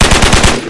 9mm Machine Gun